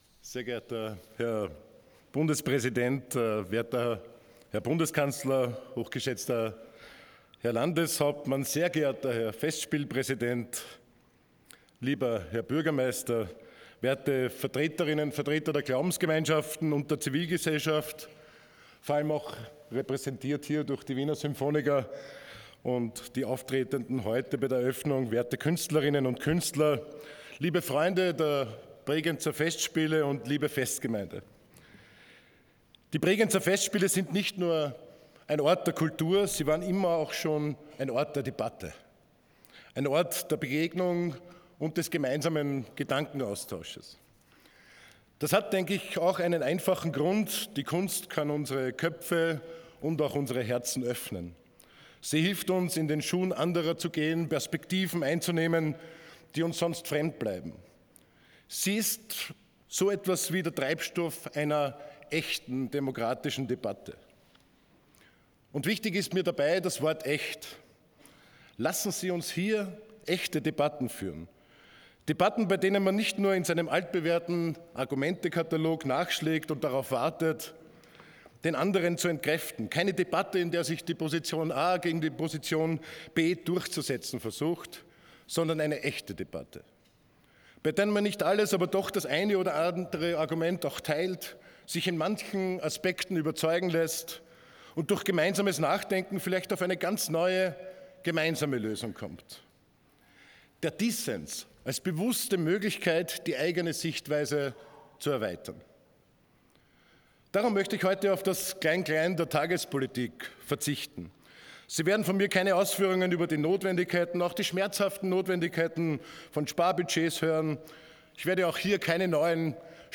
Eröffnung der Bregenzer Festspiele
Vizekanzler und Kulturminister Andreas Babler betonte am 16. Juli 2025 im Rahmen der Eröffnung der Bregenzer Festspiele, die Bedeutung der Festspiele als Ort der Kultur und Ort der Begegnung sowie des Gedankenaustauschs und warnte vor autoritären Tendenzen, die unsere Demokratie, Institutionen und Rechtsstaatlichkeit gefährden. Die Kunst könne als Treibstoff einer echten demokratischen Debatte unsere Köpfe und Herzen öffnen.
rede-andreas-babler-eroeffnung-2025.mp3